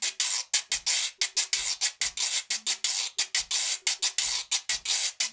90 GUIRO 1.wav